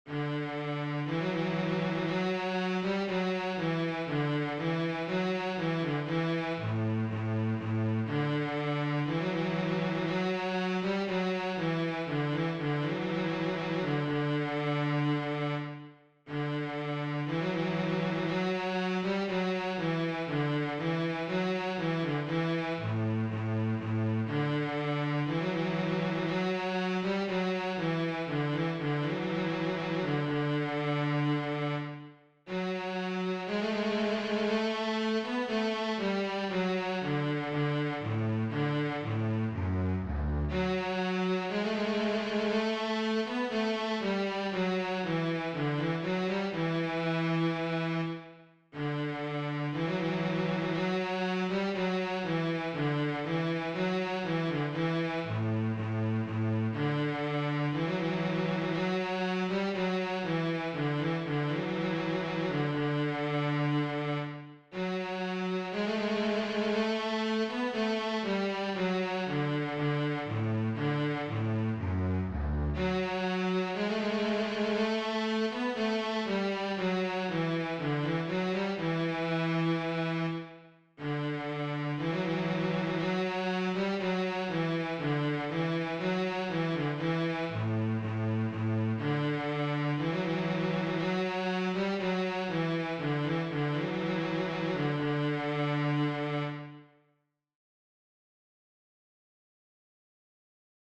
DIGITAL SHEET MUSIC - CELLO SOLO